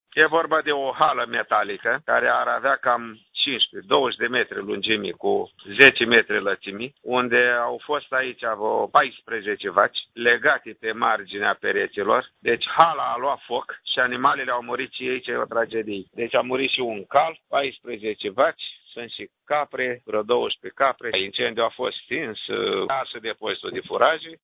Viceprimarul comunei Cârligele, Ion Baniță: